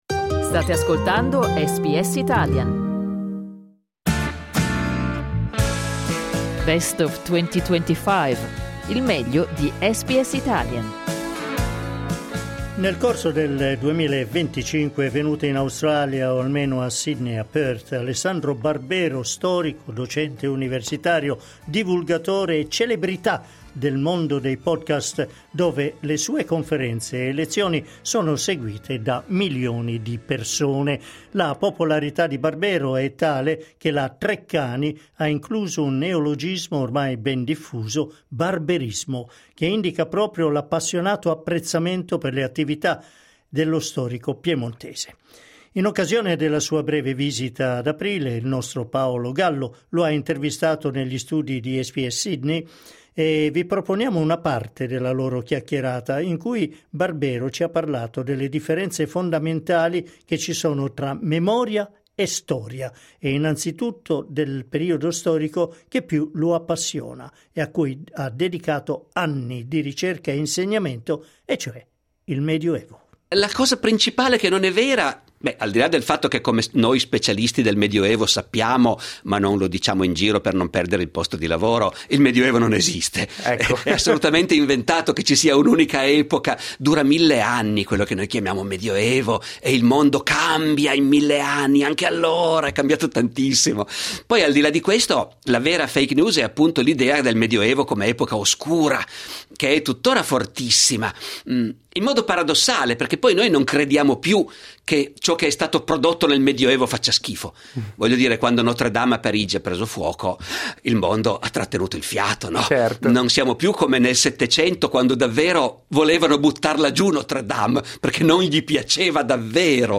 Dai nostri archivi, l'intervista dello scorso aprile ad Alessandro Barbero, celebre storico e divulgatore italiano.
Alessandro Barbero negli studi di SBS di Sydney.